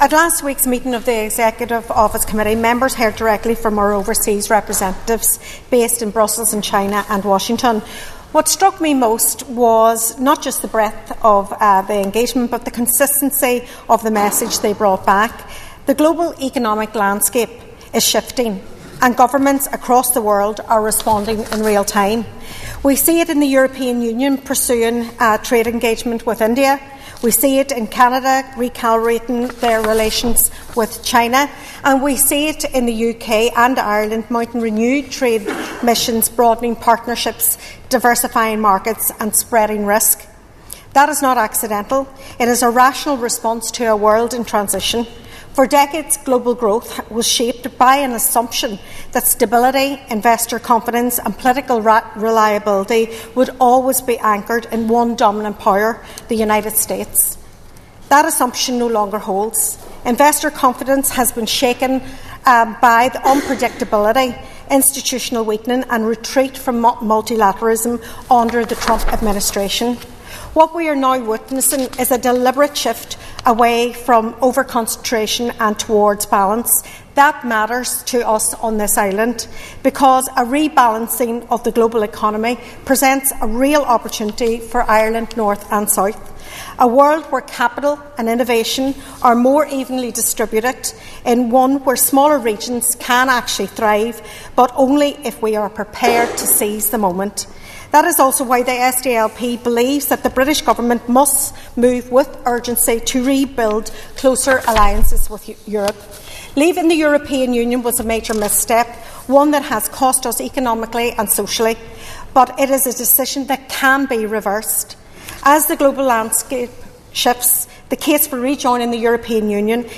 A Foyle MLA has told the Assembly that a changing global economic order means that now, more than ever, it would make sense for the UK to re-join the EU.